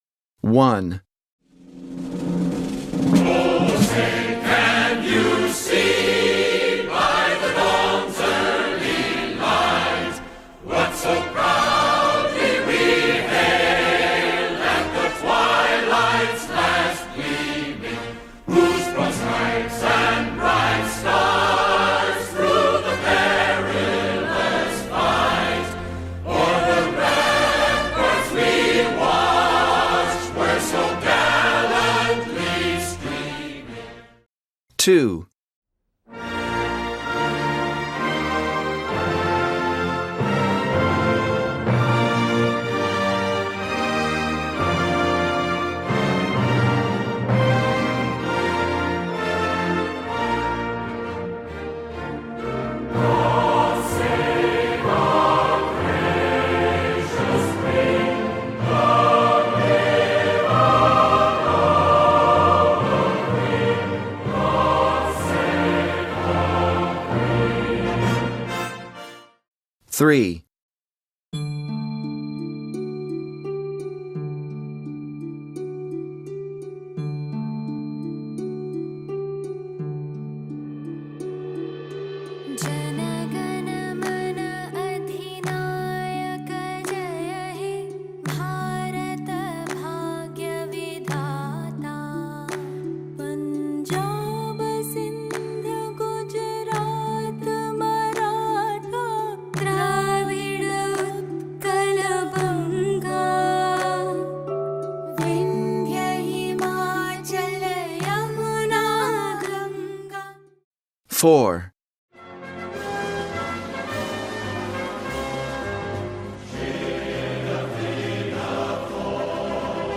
(Упражнение 3 «Угадай гимн страны» ответы на задание: Приложение 5)
(учитель ставит на паузу запись после каждого гимна и задаёт вопрос: “Which country’s national anthem is it?”